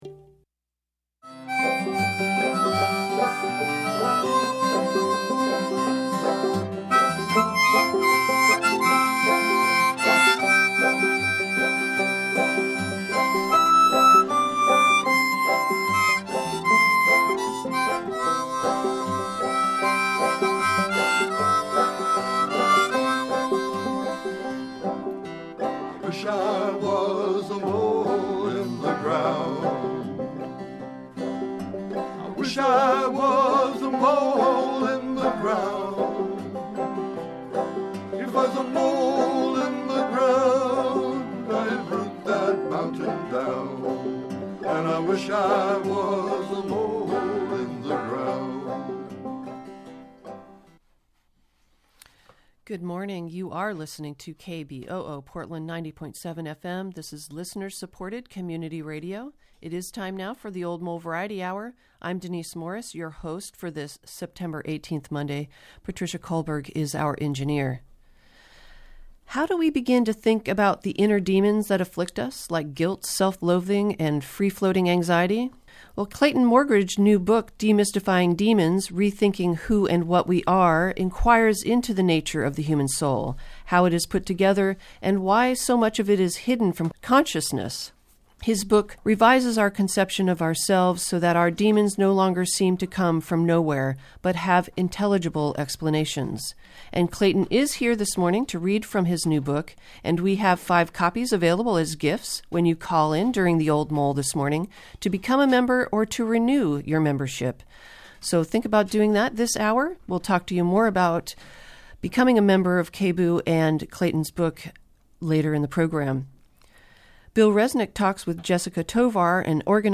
Membership Drive edition of the Old Mole